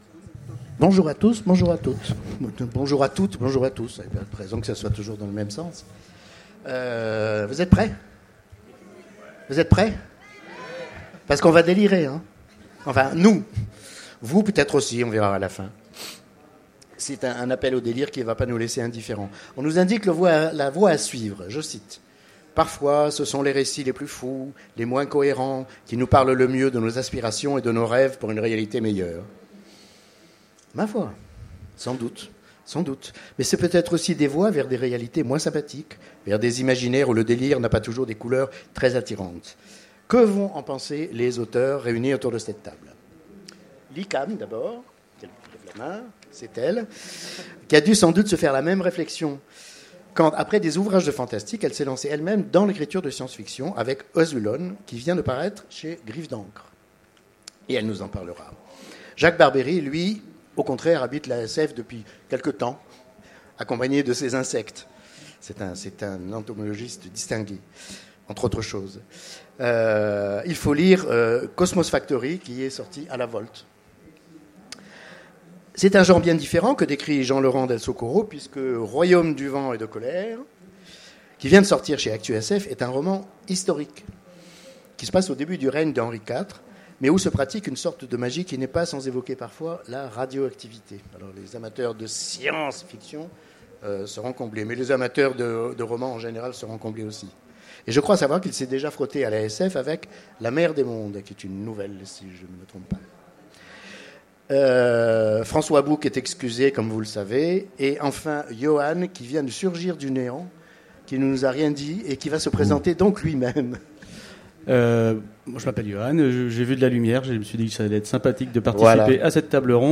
Utopiales 2015 : Conférence Délirons !